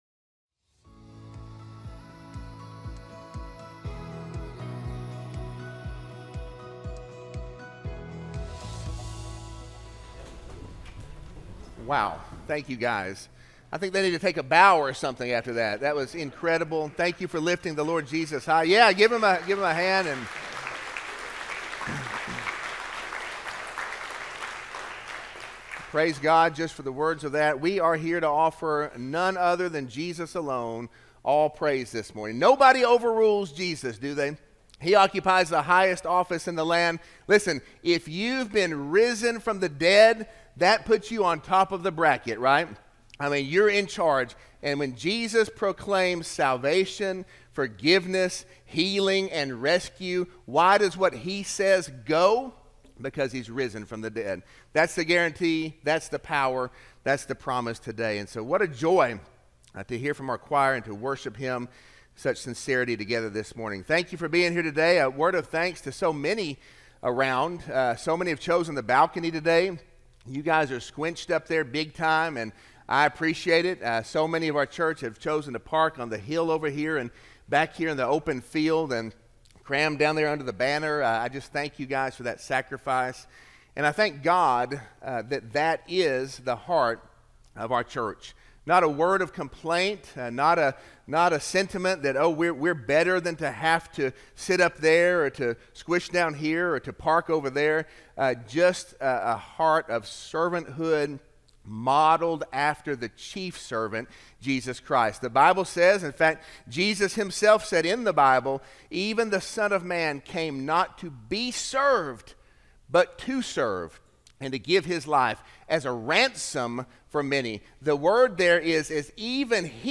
Sermon-4-20-25-audio-from-video.mp3